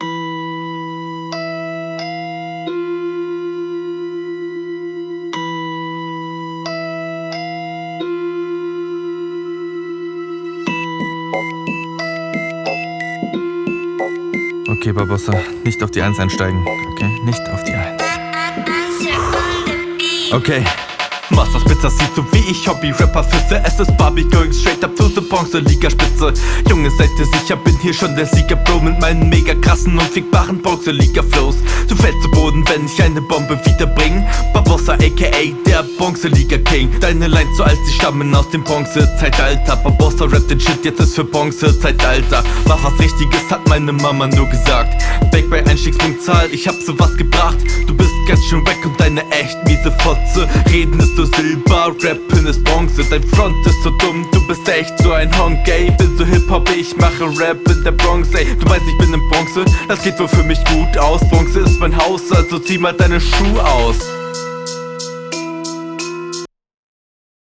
Der Flow bei der Einstiegsline war echt cool, leider blieb es nicht durchgehend auf dem …